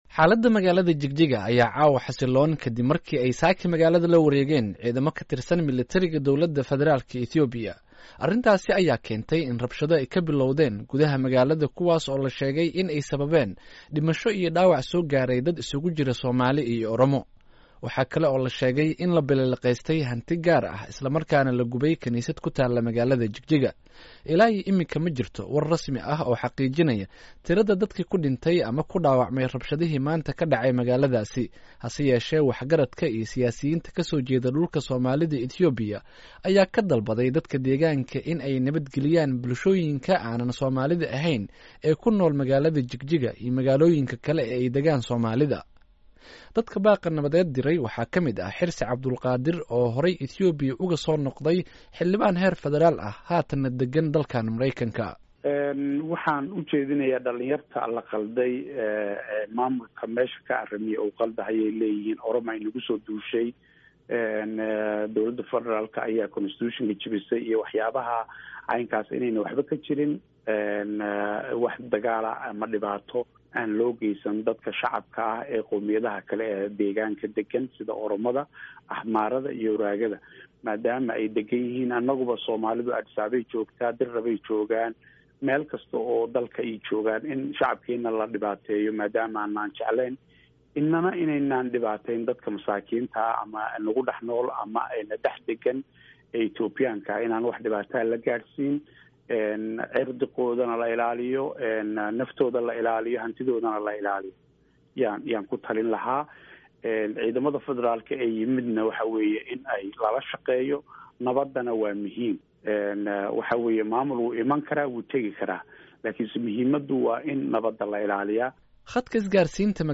Halkaan ka dhageyso warbixinta Jigjiga